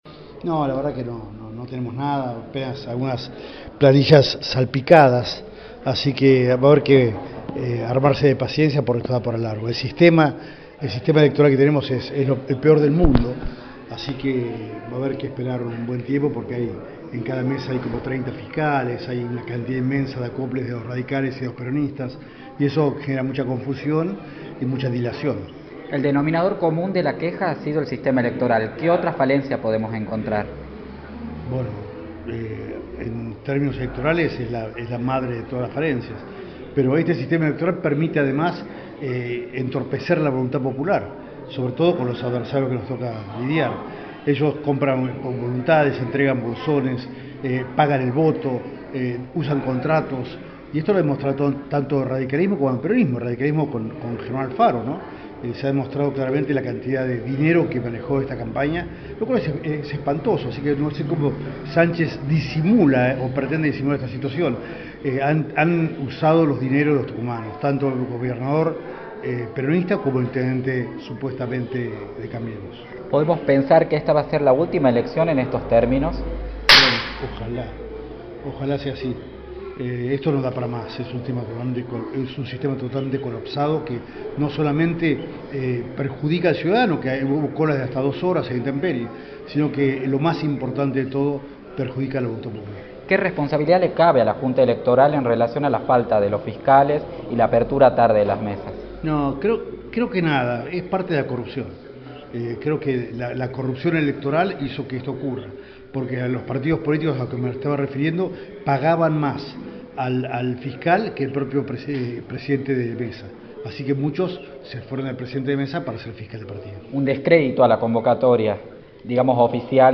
“El sistema que tenemos es el peor del mundo, en cada mesa hay 30 fiscales lo cual se presta a que haya mucha confusión” remarcó el candidato a Gobernador Ricardo Bussi, en entrevista para Radio del Plata Tucumán, por la 93.9.